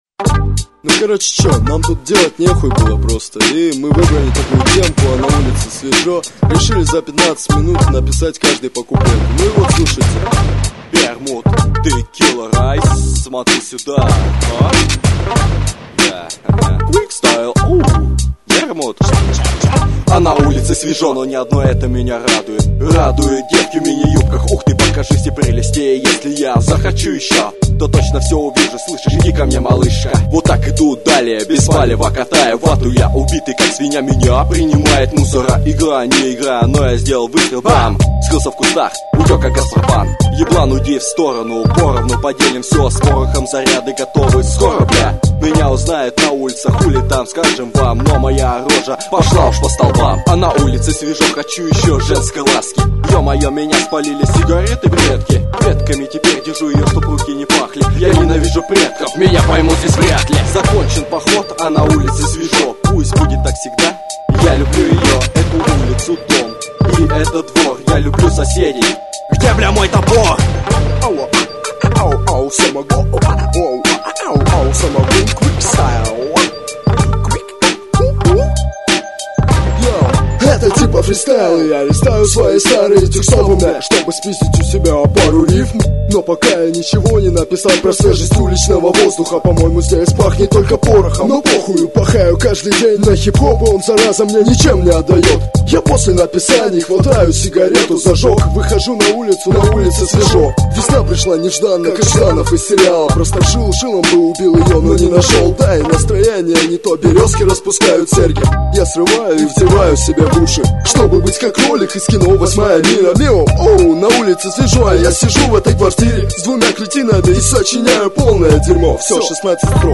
2007 Рэп